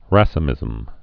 (răsə-mĭzəm, rā-sē-)